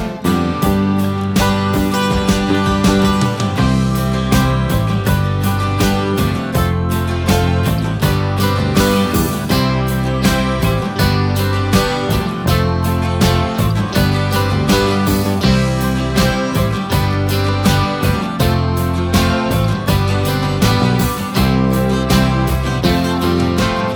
no Backing Vocals Pop (1970s) 4:24 Buy £1.50